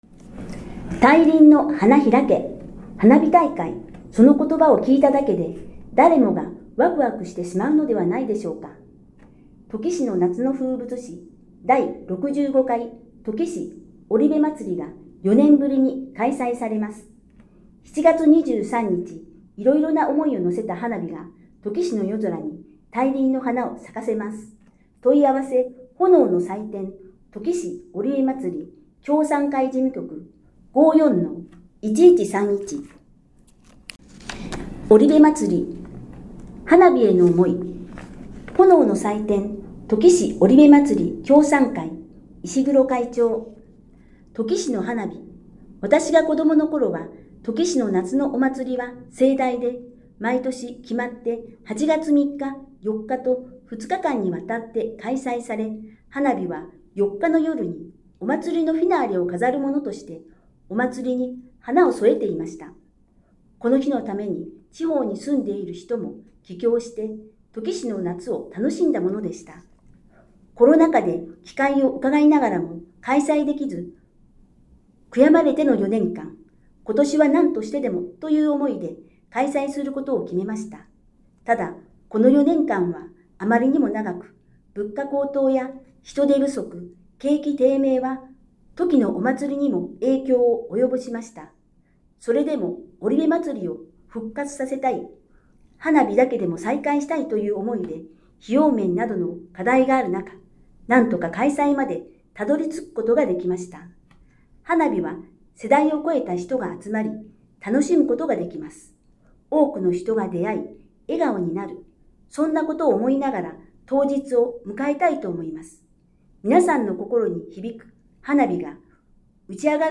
音声欄に表示があるものは、「声の広報」として音声で聞くことができます。